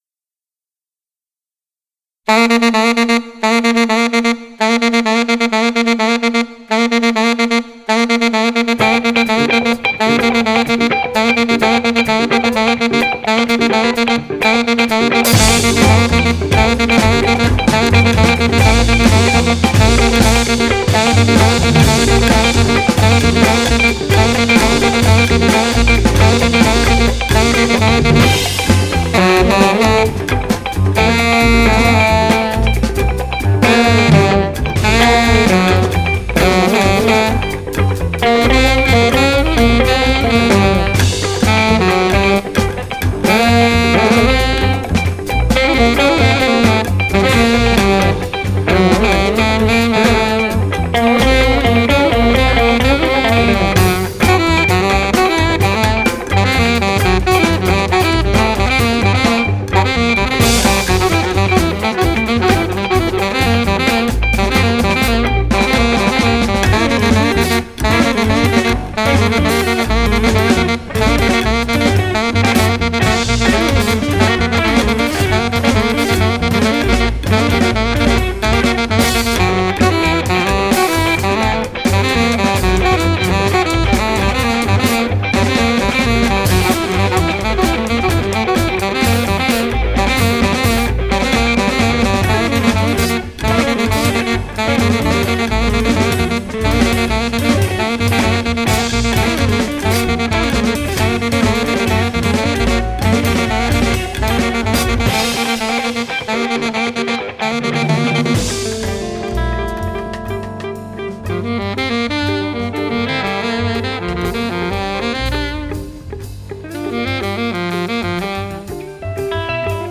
propulsive groove